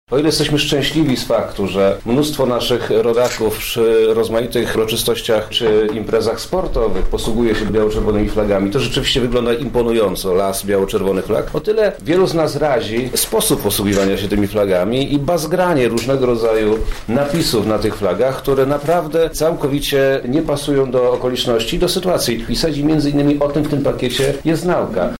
Dlaczego to takie ważne, tłumaczy Przemysław Czarnek Wojewoda Lubelski: